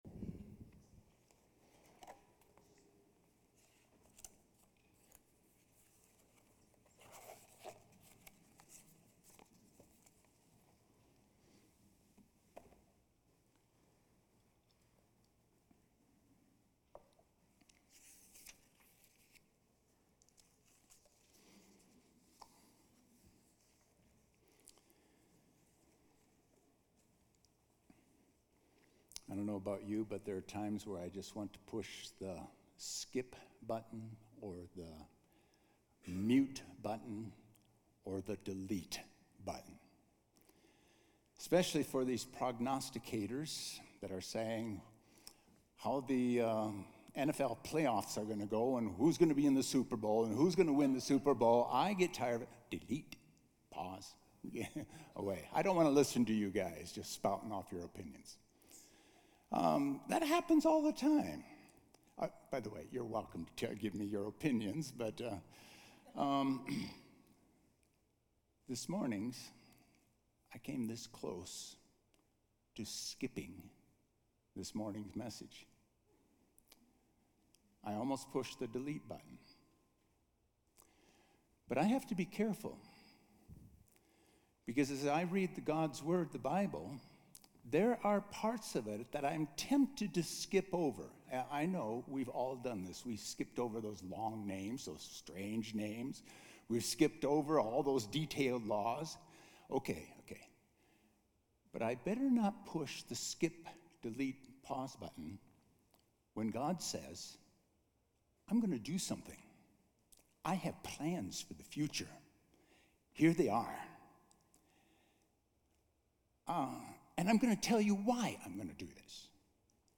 A message from the series "Jesus Return."